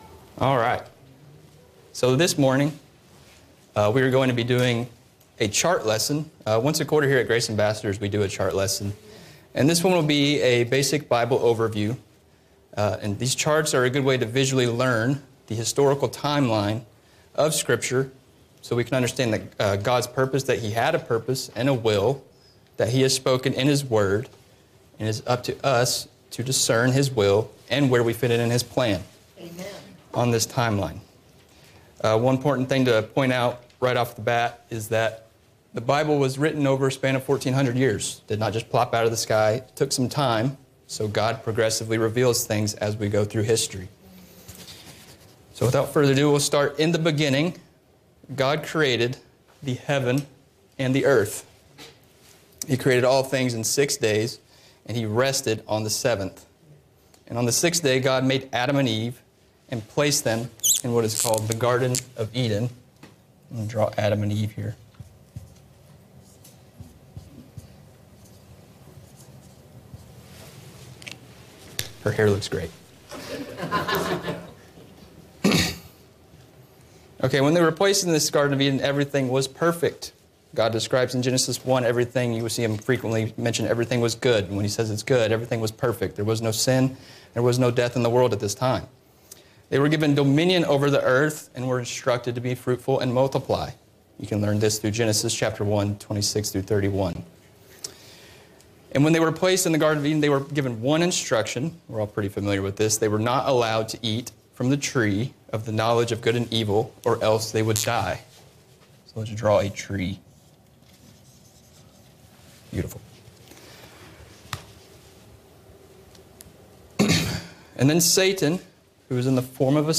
Download MP3 | Download Outline Description: Several times a year, we take time to review the content of the entire Bible in one lesson. This chart lesson explains what the Bible revealed since the world began and contrasts it with what was kept secret since the world began.